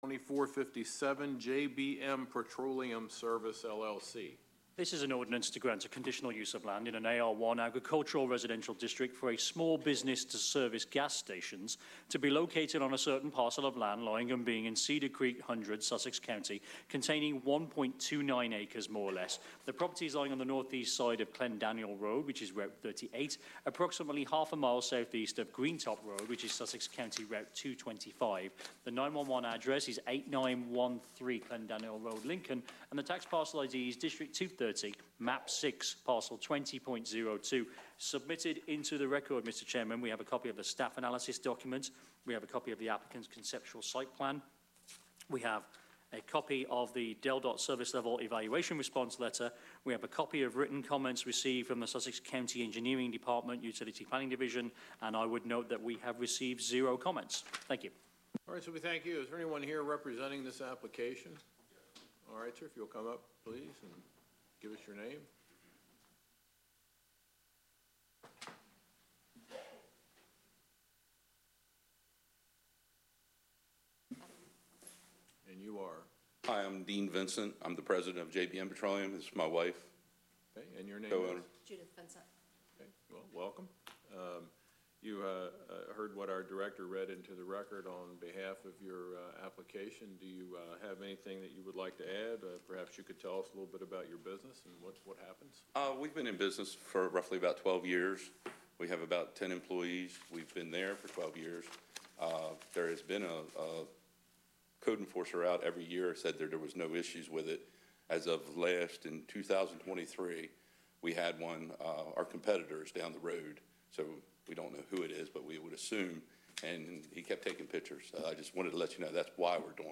5. CU 2457 JBM Petroleum Service, LLC - Public Hearing.mp3